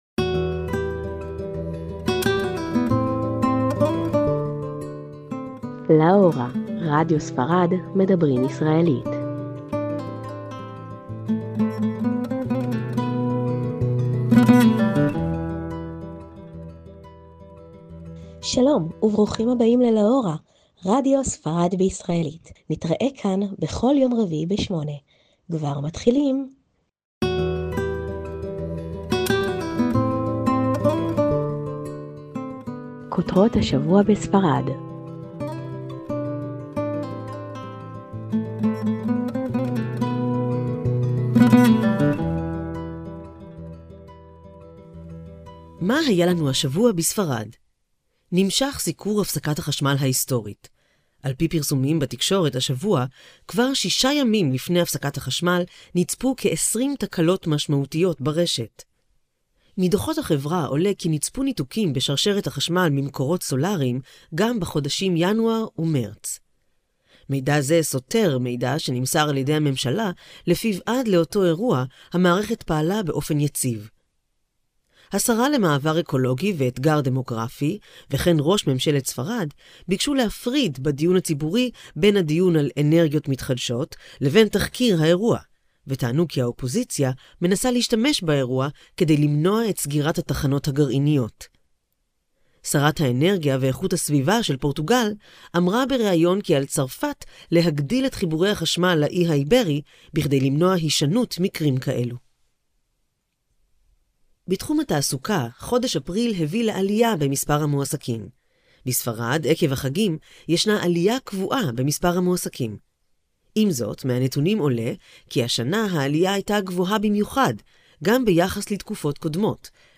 תכנית רדיו בעברית לטובת הישראלים בספרד